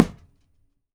PUNCH B   -S.WAV